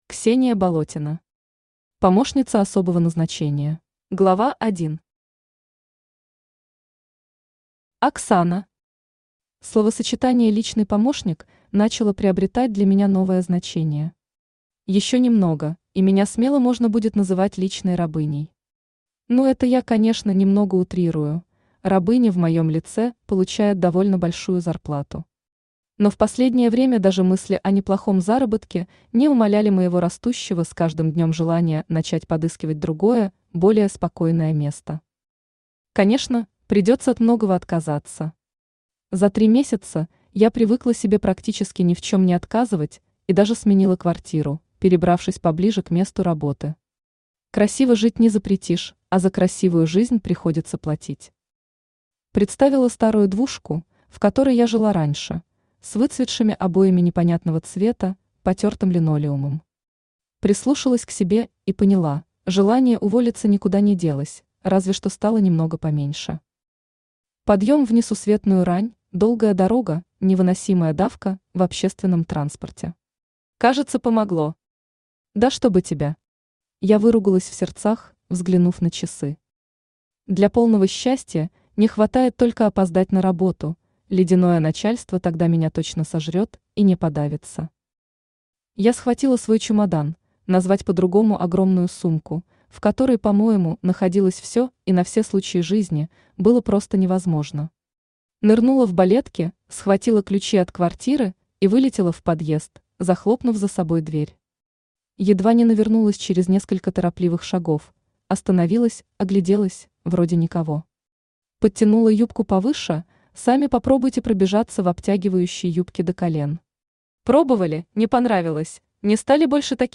Aудиокнига Помощница особого назначения Автор Ксения Болотина Читает аудиокнигу Авточтец ЛитРес. Прослушать и бесплатно скачать фрагмент аудиокниги